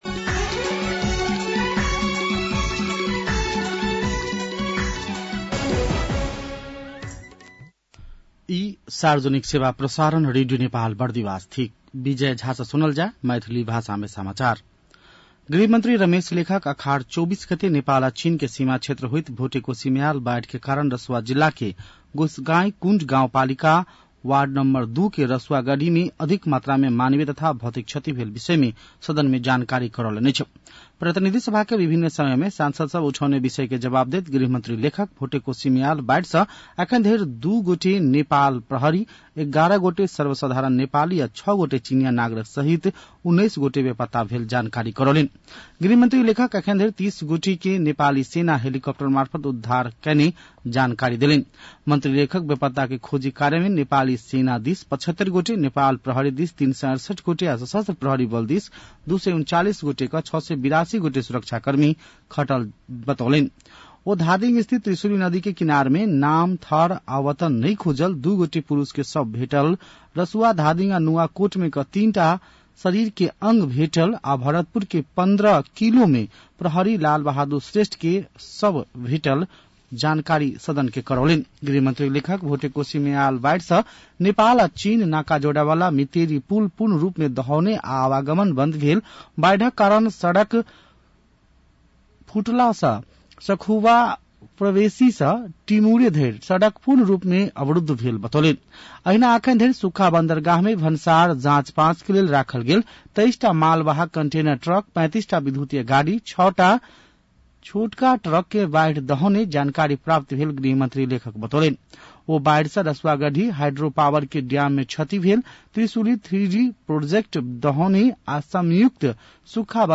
मैथिली भाषामा समाचार : २५ असार , २०८२